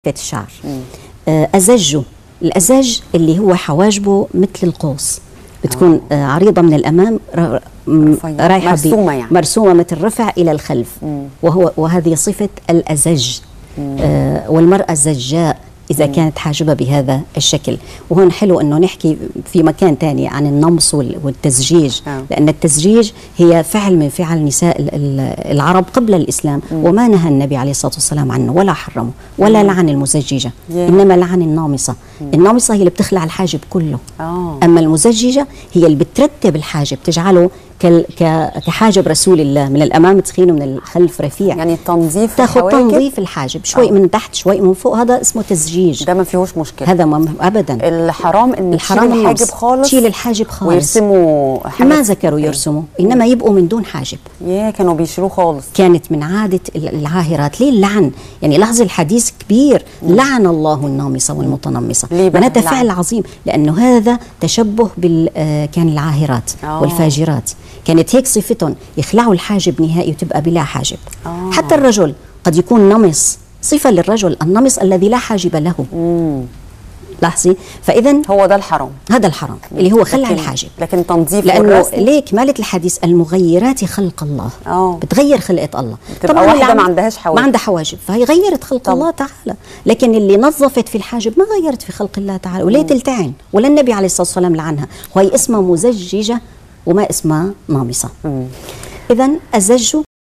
المقابلة